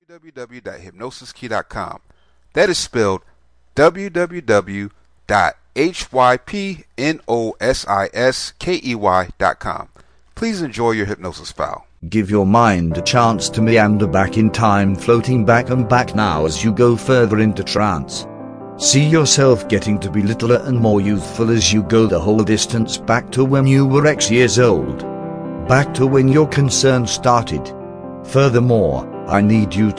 New Beginnings Changing Personal History Self Hypnosis Mp3. This Self Hypnosis will help you create a new beginning in your life.